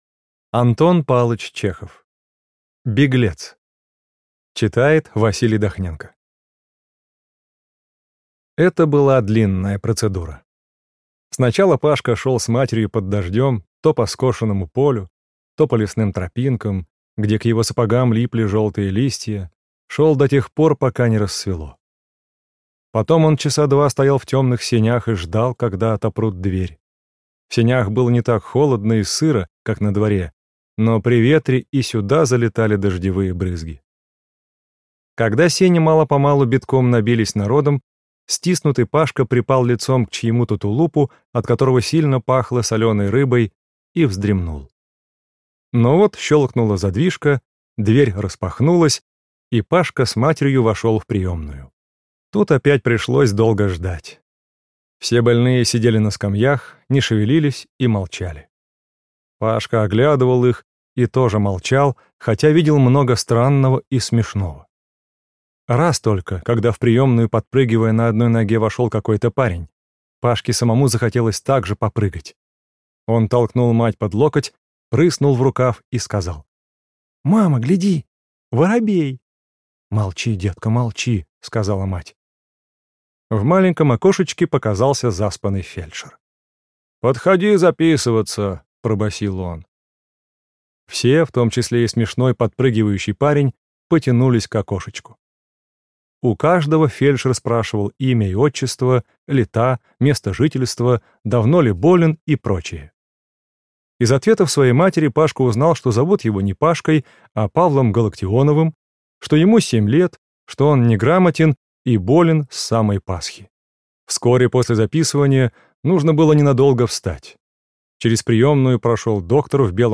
Беглец - аудио рассказ Чехова - слушать онлайн